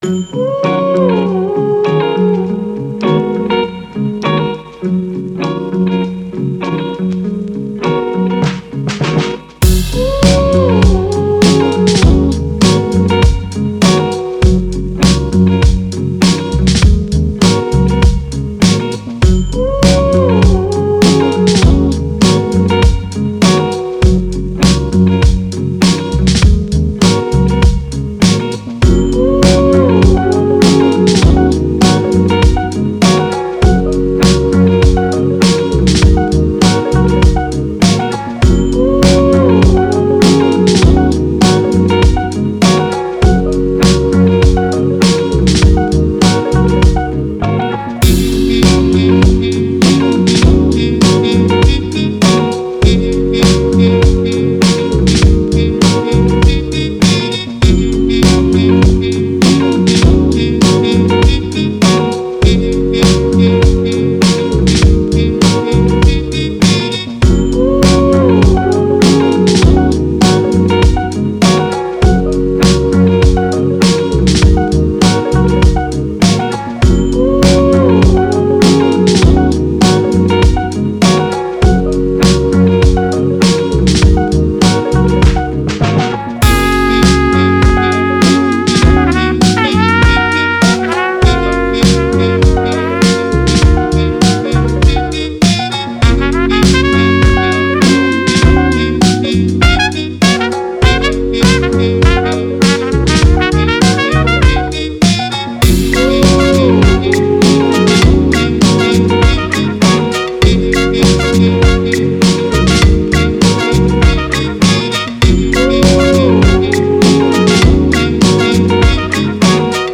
Soul, Vintage, Vibe, Happy, Joy